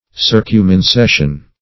Search Result for " circumincession" : The Collaborative International Dictionary of English v.0.48: Circumincession \Cir`cum*in*ces"sion\, n. [Pref. circum- + L. incedere, incessum, to walk.]